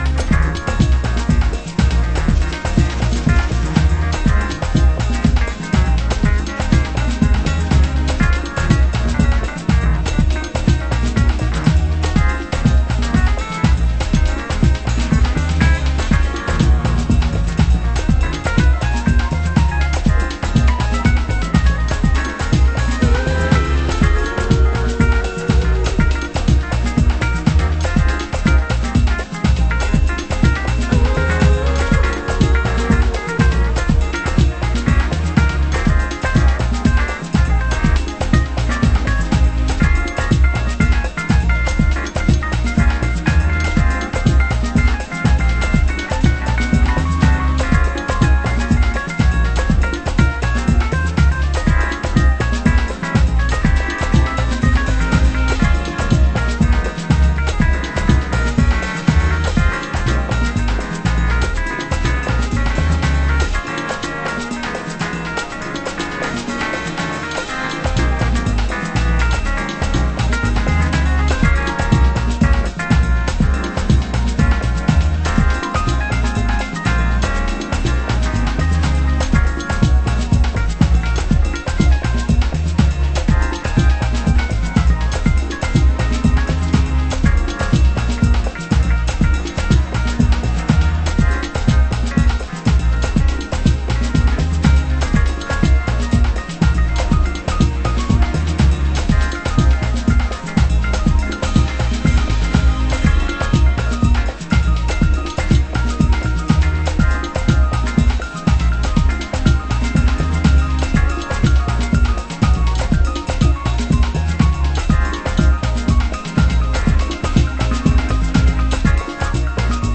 Main Vocal Version